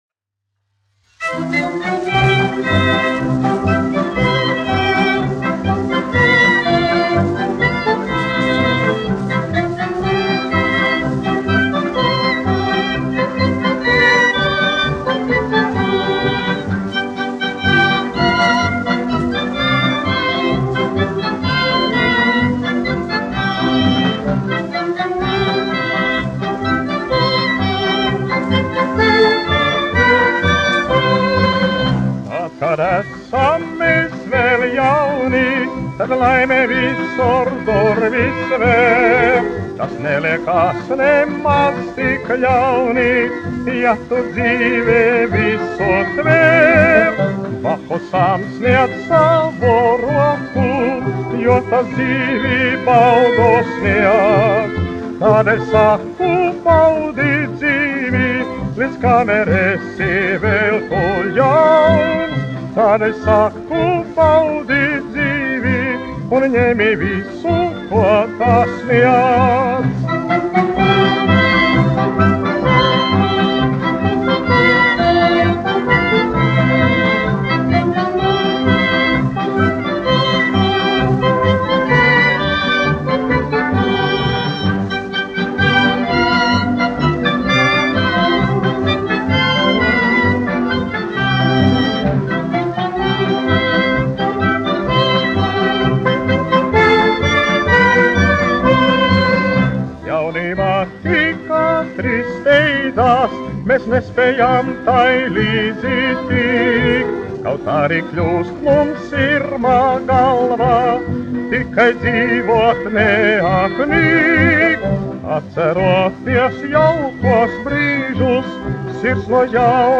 1 skpl. : analogs, 78 apgr/min, mono ; 25 cm
Marši
Fokstroti
Populārā mūzika
Skaņuplate